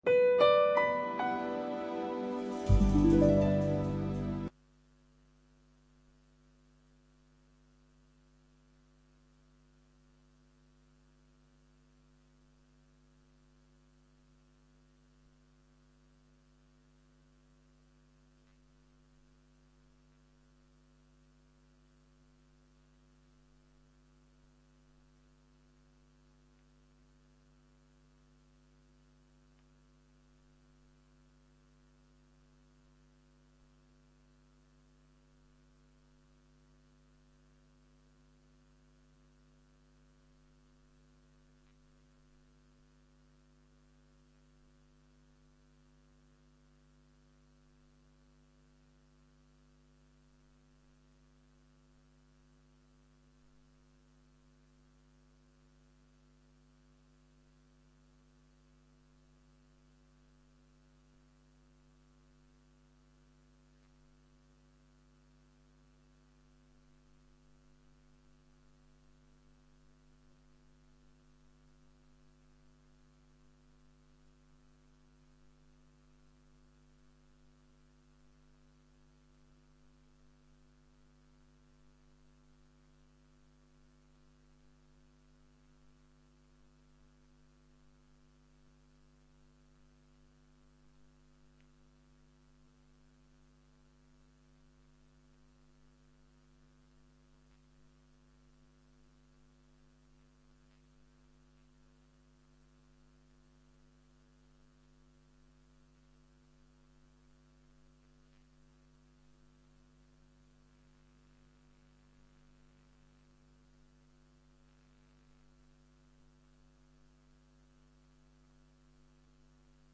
Inauguración y conferencia